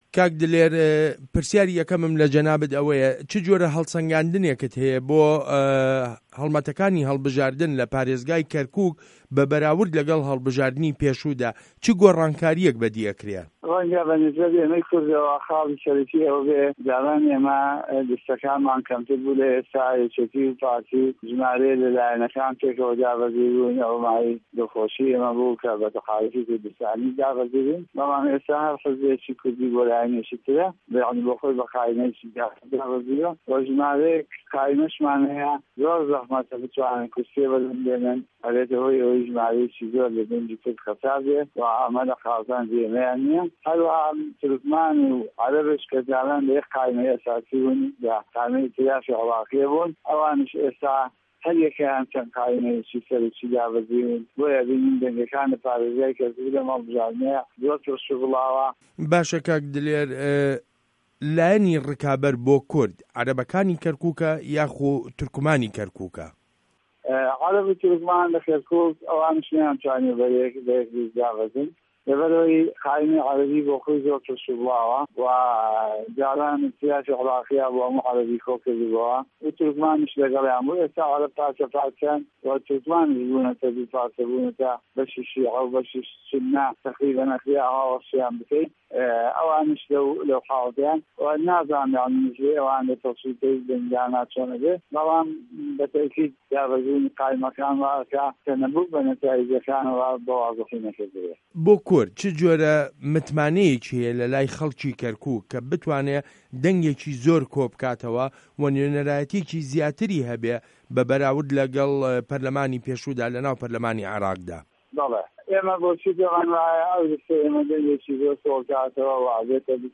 وتووێژ له‌گه‌ڵ دلێر عه‌بدولقادر